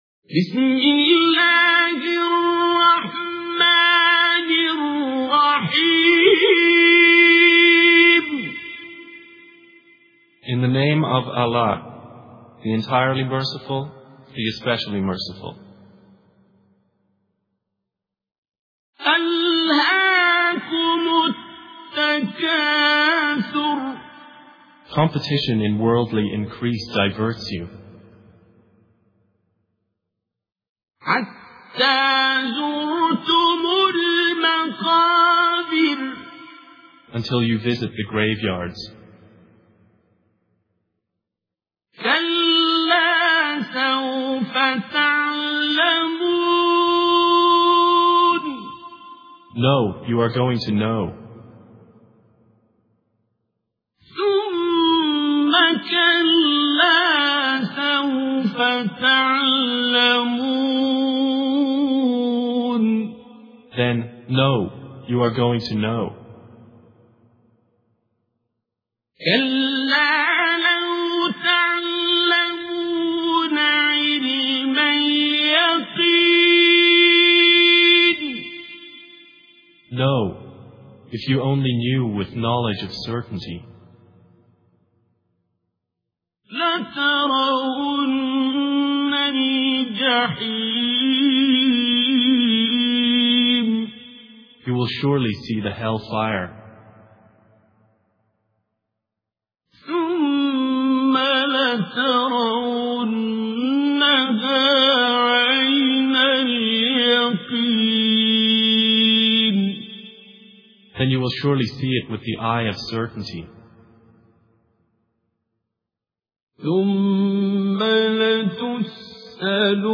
اللغة الإنجليزية التلاوة بصوت الشيخ عبدالباسط عبد الصمد